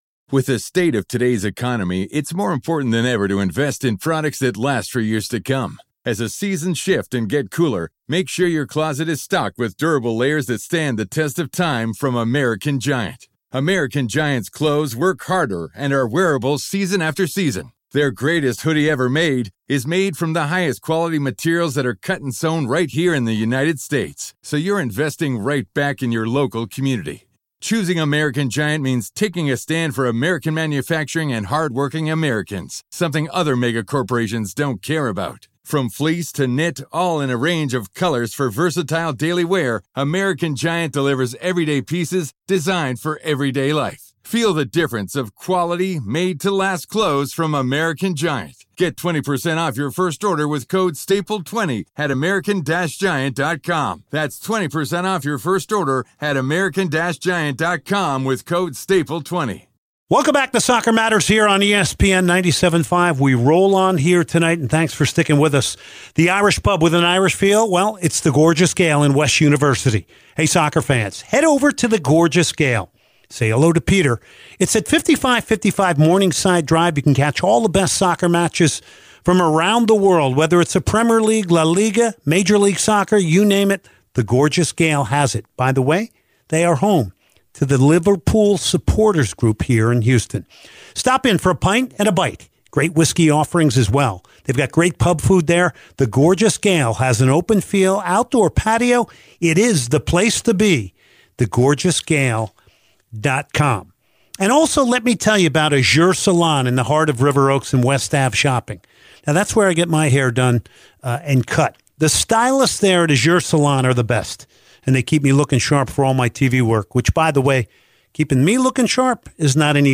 Geoff Cameron Interview